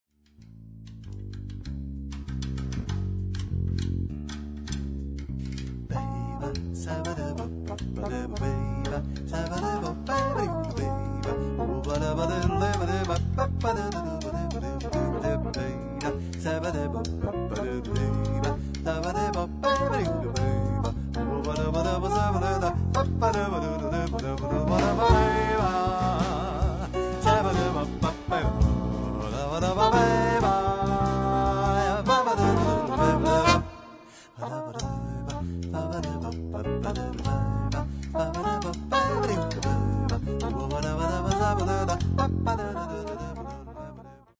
a metà strada tra la melodia popolare e il jazz.